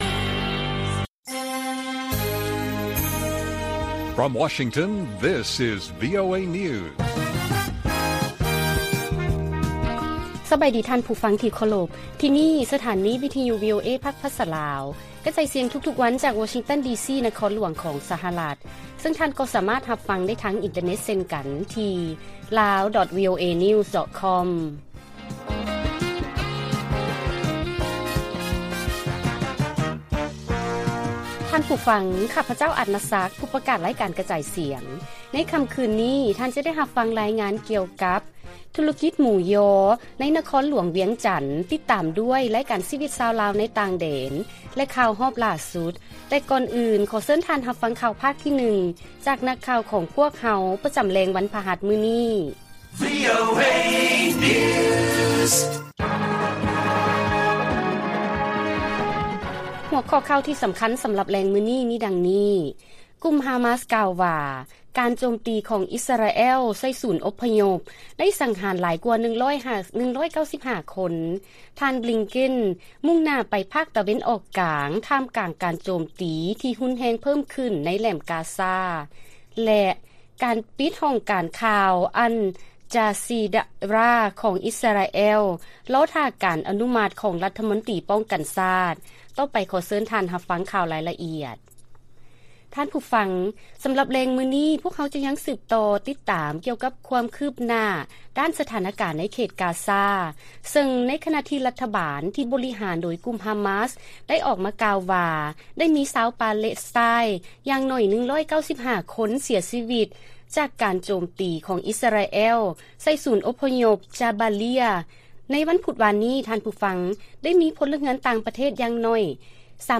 ລາຍການກະຈາຍສຽງຂອງວີໂອເອ ລາວ: ກຸ່ມຮາມາສ ກ່າວວ່າ ການໂຈມຕີຂອງອິສຣາແອລ ໃສ່ສູນອົບພະຍົບ ໄດ້ສັງຫານຫຼາຍກວ່າ